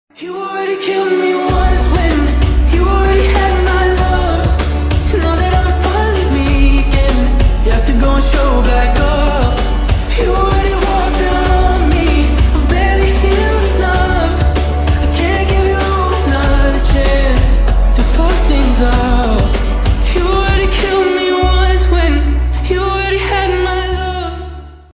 • Качество: 320, Stereo
лирика
грустные
красивый мужской голос